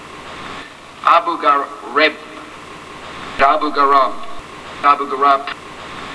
Bush had trouble pronouncing "Abu Ghraib" when he gave his speech yesterday. Three times the name came up (all in a single paragraph).
... the Republican president, long known for verbal and grammatical lapses, stumbled on the first try, calling it "abugah-rayp". The second version came out "abu-garon", the third attempt sounded like "abu-garah".
bush-abu-ghraib.wav